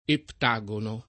vai all'elenco alfabetico delle voci ingrandisci il carattere 100% rimpicciolisci il carattere stampa invia tramite posta elettronica codividi su Facebook ettagono [ ett #g ono ] o eptagono [ ept #g ono ] s. m. (matem.)